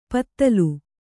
♪ pattalu